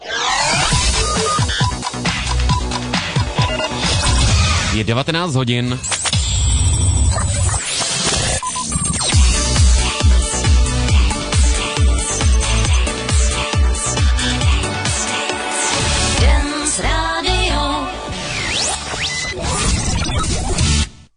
DOSAVADNÍ OTVÍRÁK V 19:00